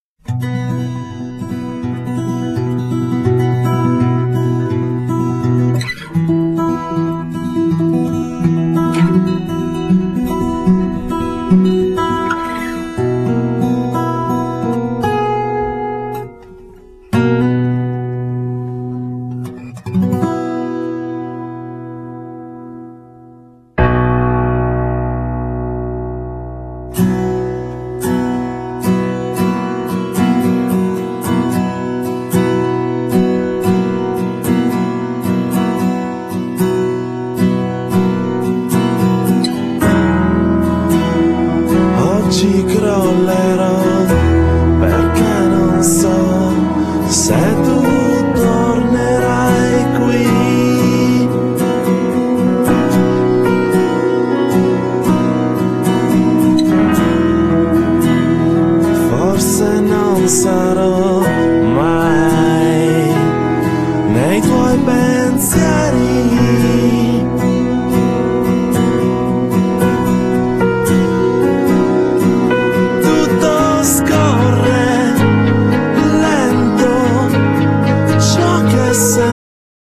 Genere : Pop rock